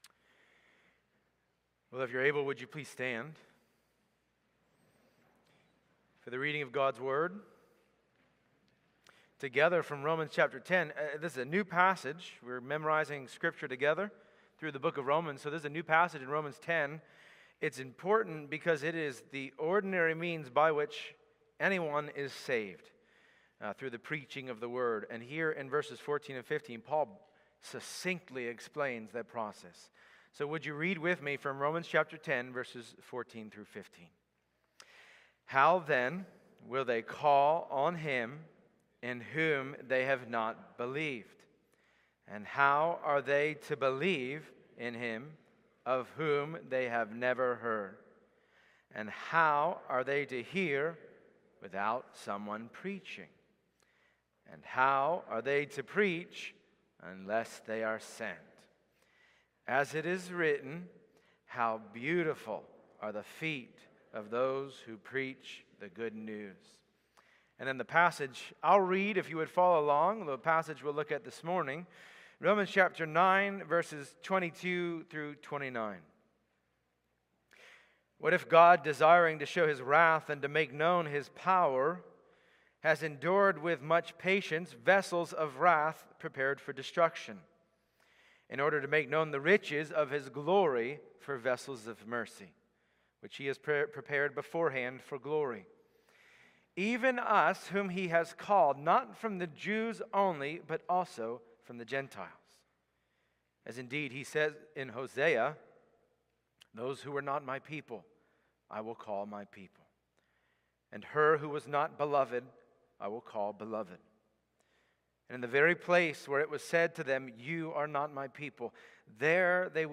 Passage: Romans 9:22-29 Service Type: Sunday Morning Download Files Bulletin « The Sovereignty of God